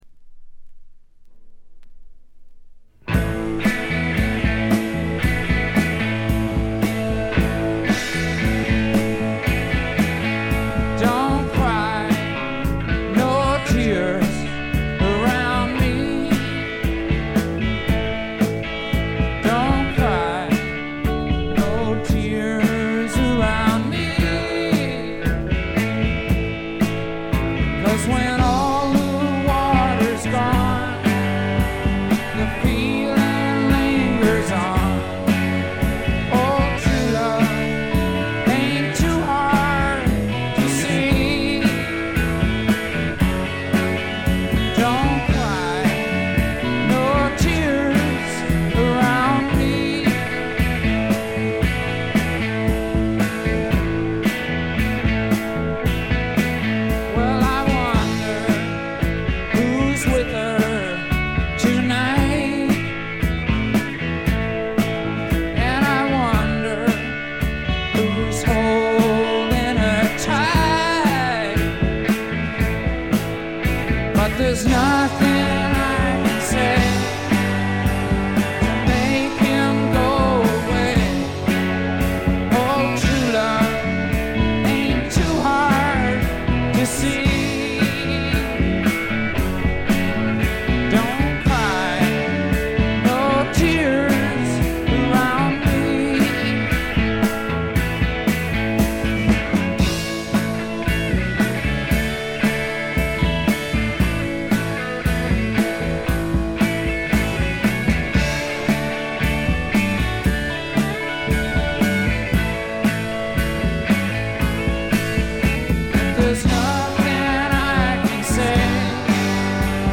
*** LP ： USA 1975
ごくわずかなノイズ感のみ。
試聴曲は現品からの取り込み音源です。